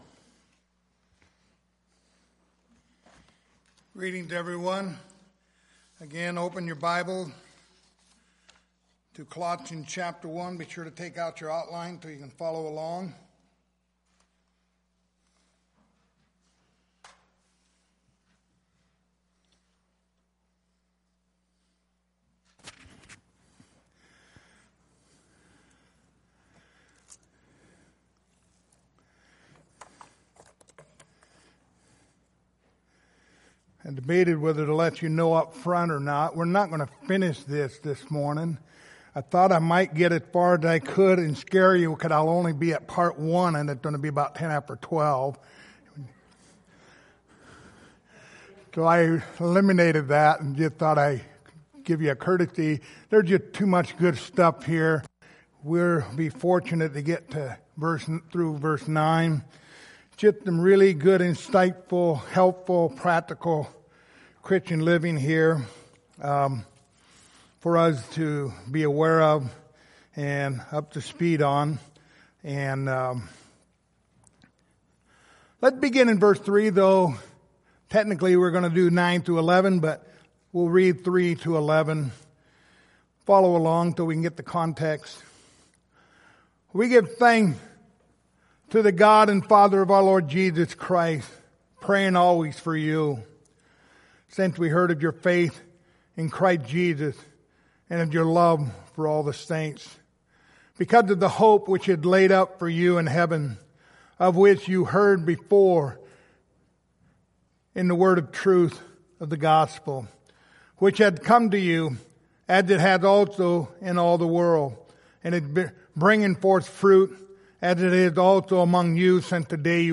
Passage: Colossians 1:9-11 Service Type: Sunday Morning